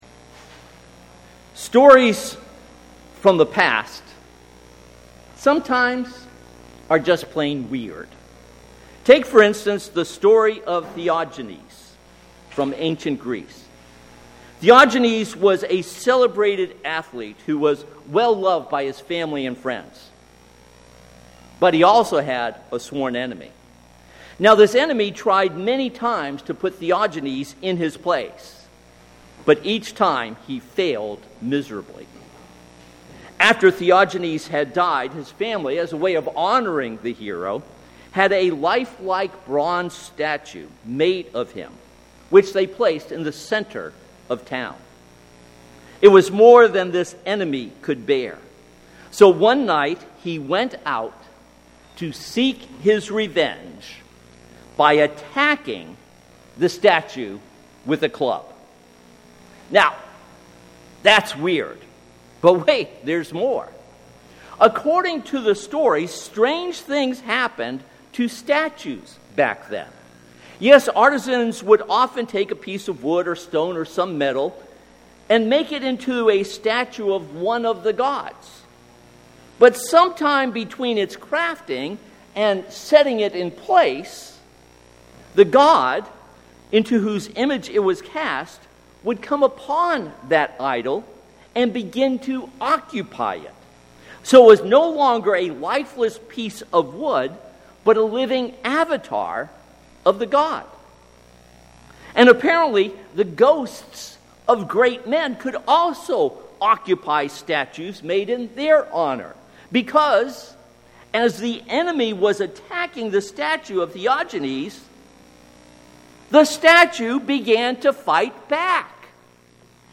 This sermon is based on Romans 12:1-2.